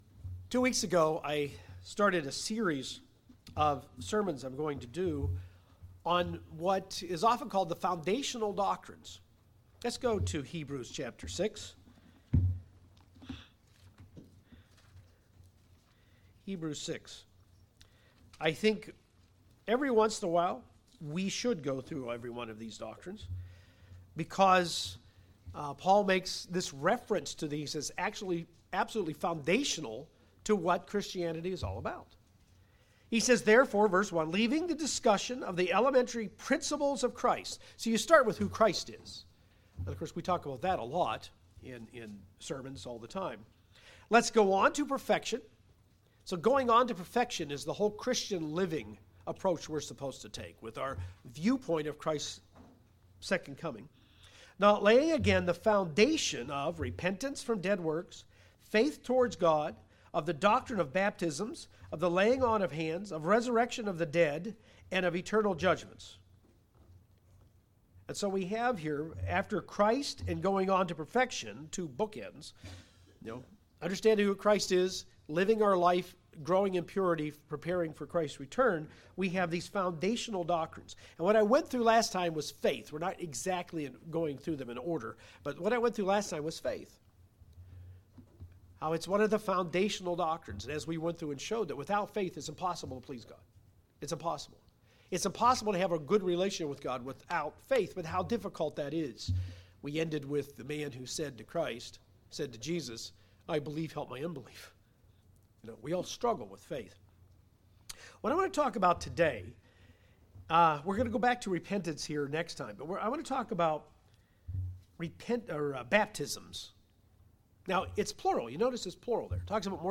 Where this practice originates, what it signifies and how it's done are covered in this message. This is the third sermon in a series covering the fundamental teachings of Hebrews 6.